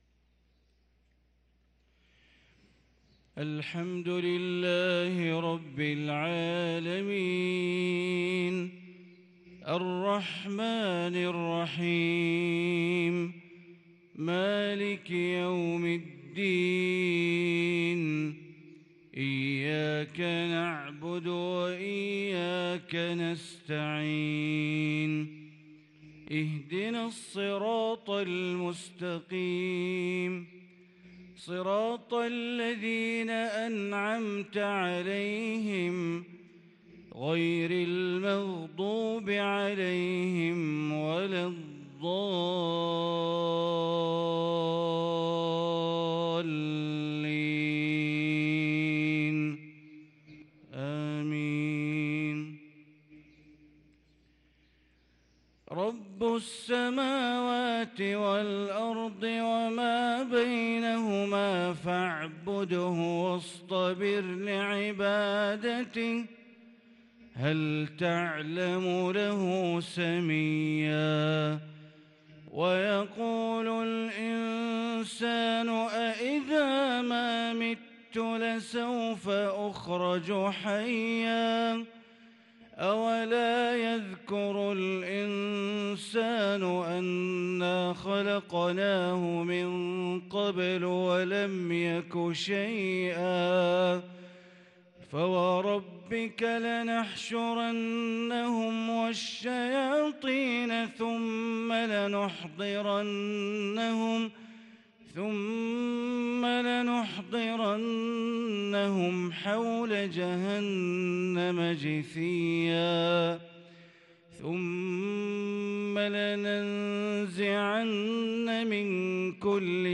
صلاة الفجر للقارئ بندر بليلة 7 ربيع الآخر 1444 هـ
تِلَاوَات الْحَرَمَيْن .